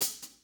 Dro HiHat 3.wav